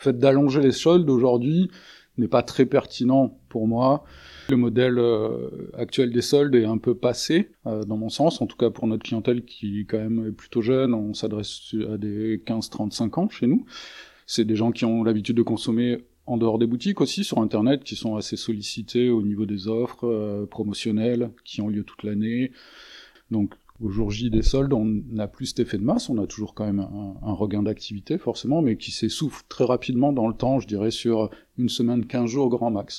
Dans le centre-ville de Pau, les deux semaines de soldes d'hiver de plus divisent clients et commerçants.
Au centre-ville de Pau, les clients sont partagés sur la prolongation de la période de soldes.